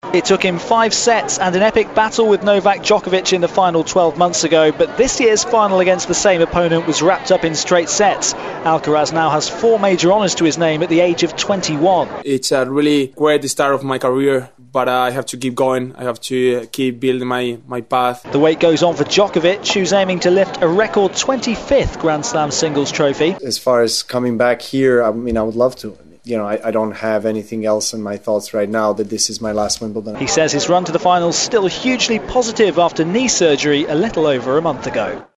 reports from Wimbledon Centre Court.